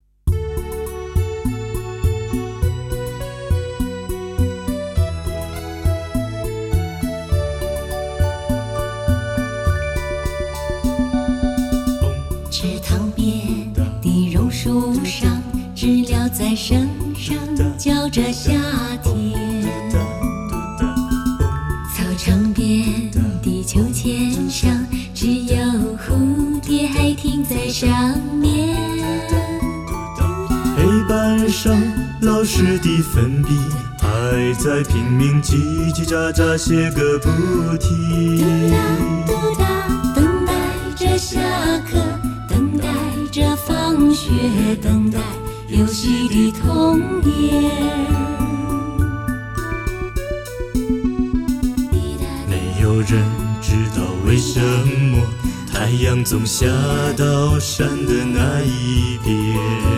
老歌新唱，感受环绕音效的包围，